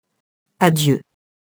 adieu [adjø] interjection et nom masculin (de à et Dieu)